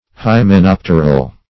Search Result for " hymenopteral" : The Collaborative International Dictionary of English v.0.48: Hymenopteral \Hy`me*nop"ter*al\, Hymenopterous \Hy`me*nop"ter*ous\, a. (Zool.)
hymenopteral.mp3